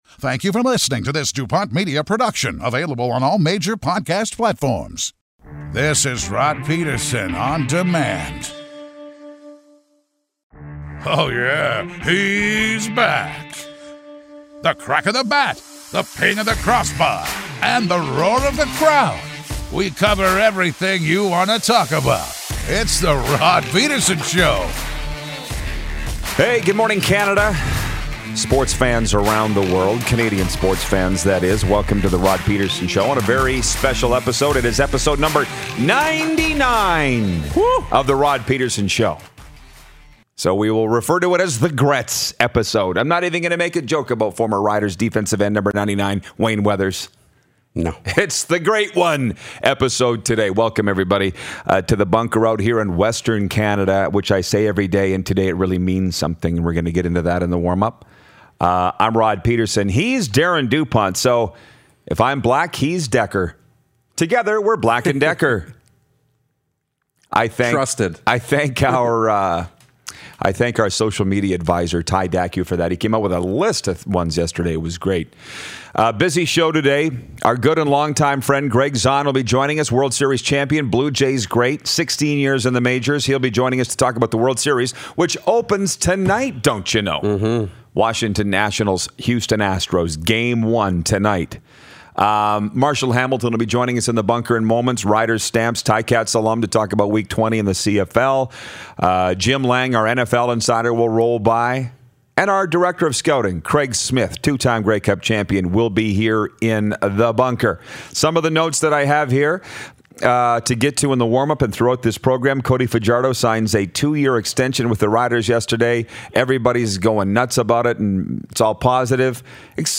Gregg Zaun, 16-Year MLB Veteran and World Series Champ, calls in!